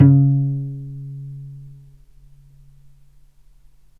healing-soundscapes/Sound Banks/HSS_OP_Pack/Strings/cello/pizz/vc_pz-C#3-mf.AIF at 2ed05ee04e9b657e142e89e46e1b34c4bb45e5a5
vc_pz-C#3-mf.AIF